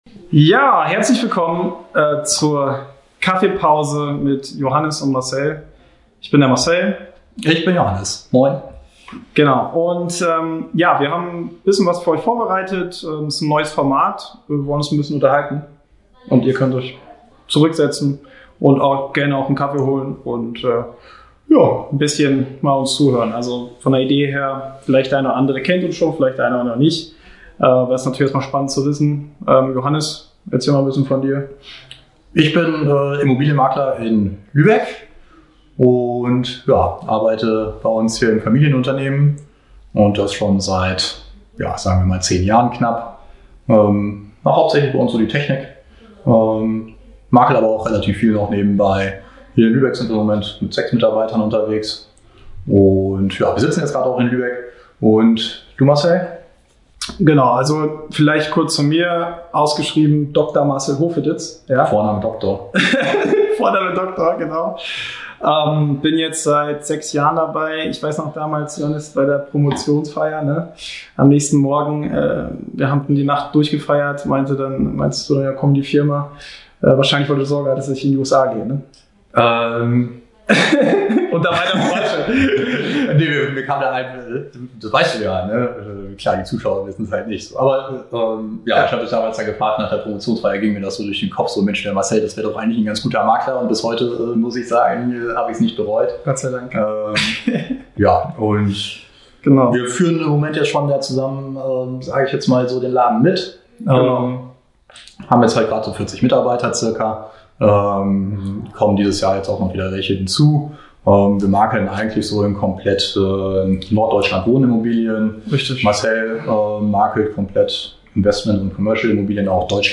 Wir haben uns gedacht, wir berichten einfach mal ungeskriptet und unverfälscht direkt aus unserem Immobilienmakler-Alltag und quatschen einfach über unterschiedliche Themen aus der Immobilien-Branche.